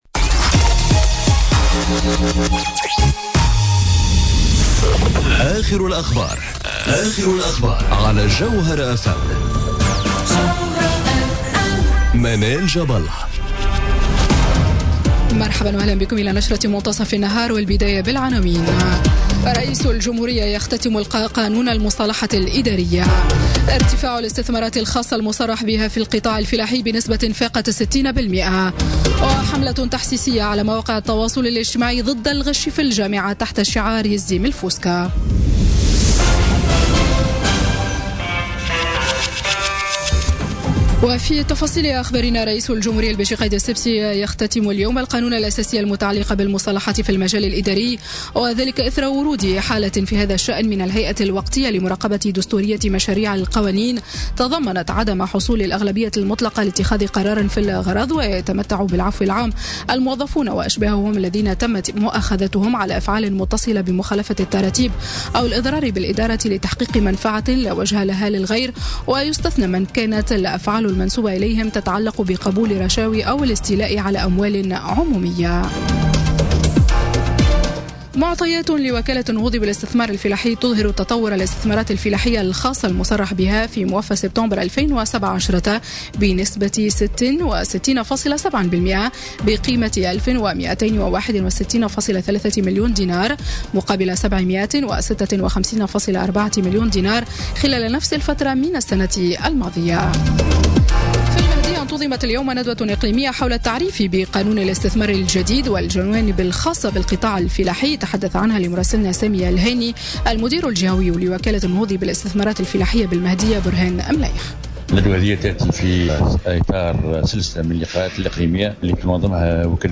نشرة أخبار منتصف النهار ليوم الثلاثاء 24 أكتوبر 2017